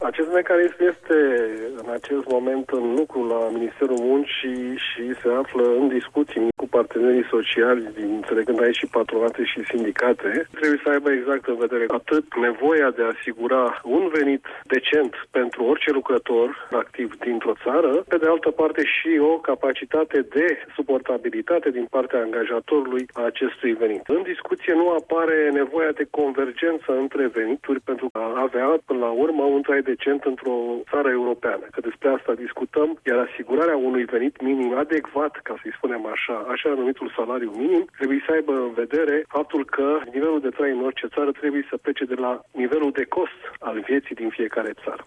Ministrul Investiţiilor şi Proiectelor Europene, Adrian Câciu, a declarat la RRA că în stabilirea salariului minim trebuie trebuie avute în vedere mai multe componente sociale: